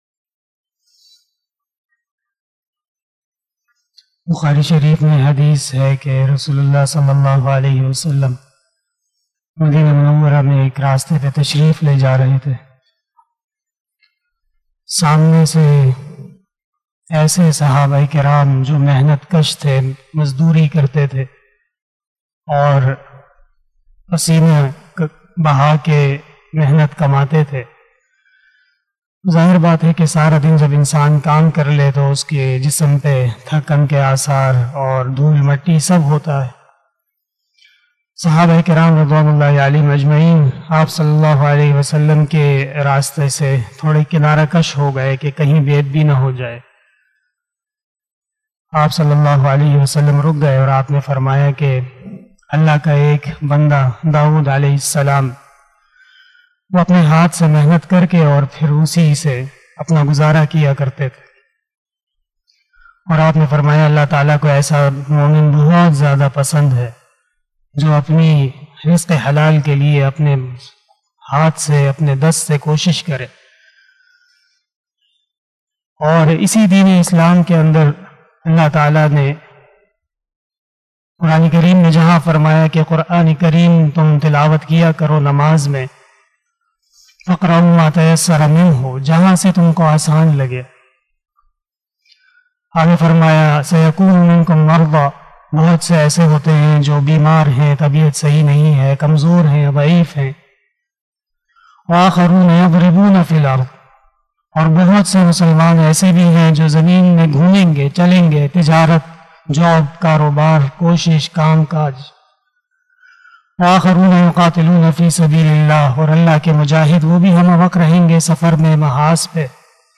030 After Isah Namaz Bayan 06 July 2021 (25 Zulqadah 1442HJ) Wednesday